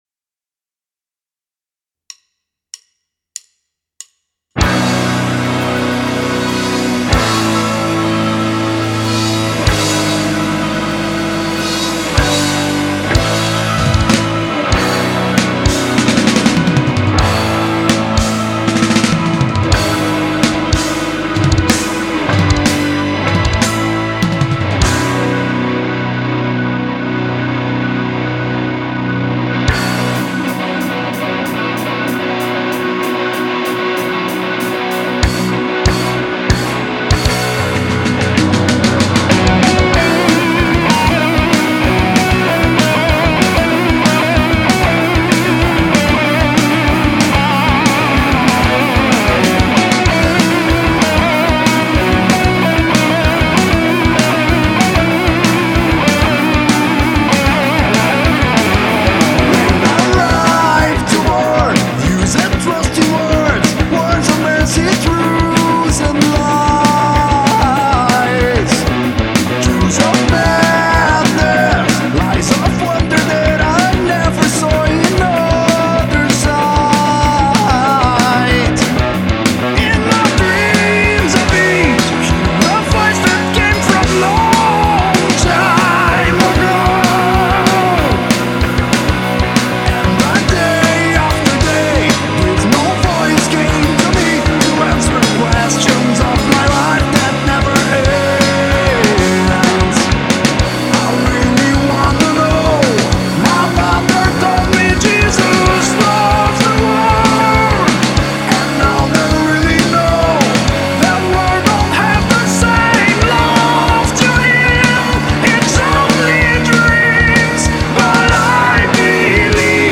EstiloRock
guitarra solo